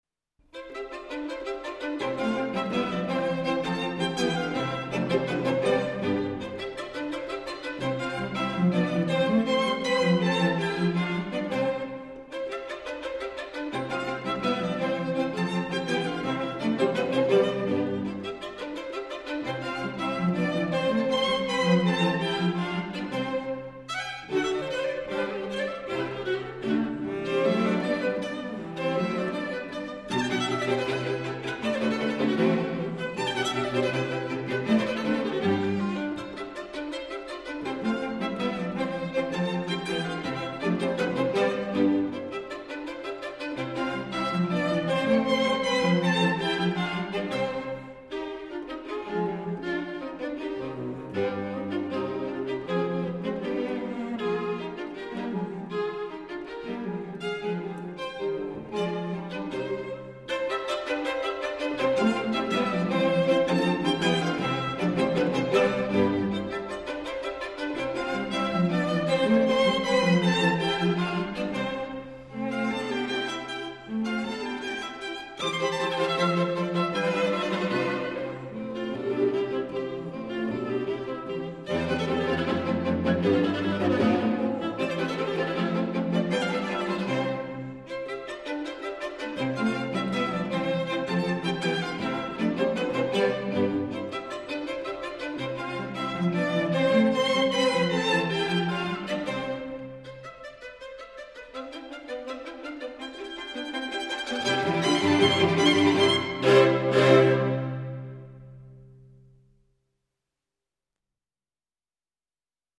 String Quartet in C major
Presto